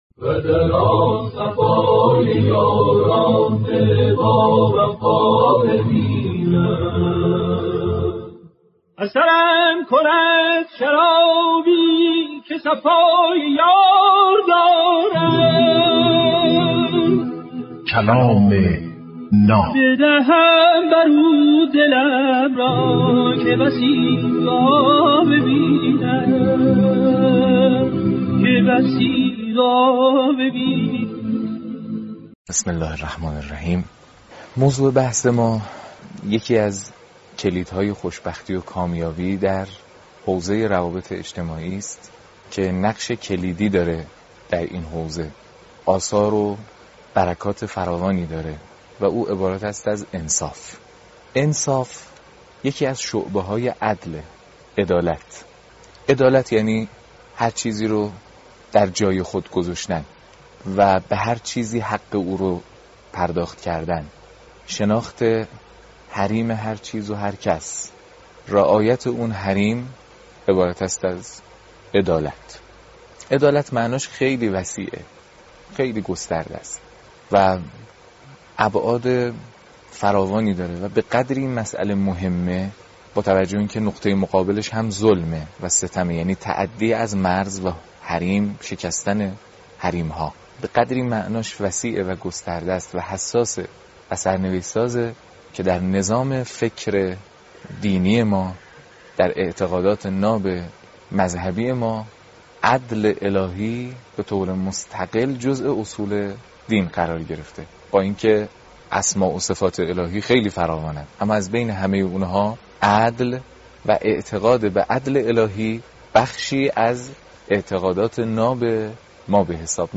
کلام ناب برنامه ای از سخنان بزرگان است